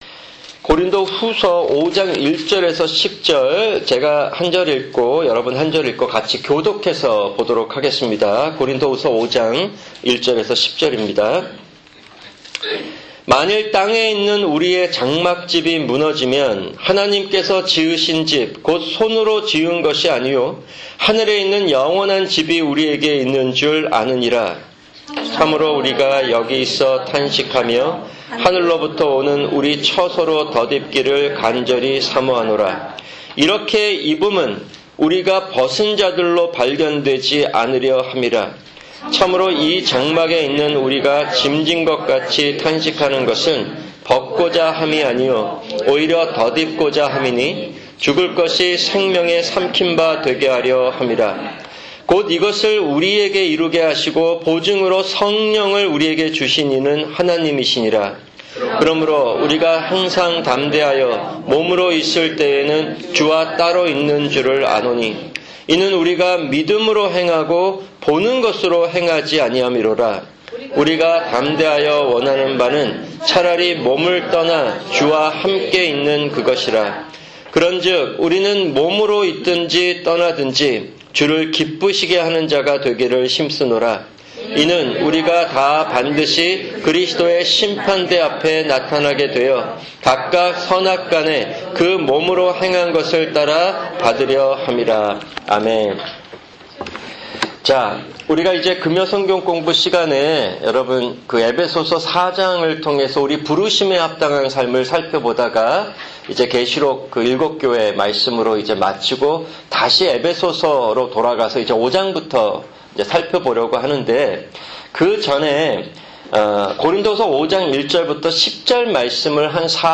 [금요 성경공부] 고린도후서 5:1-10(1)